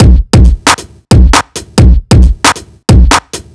Overload Break 1 135.wav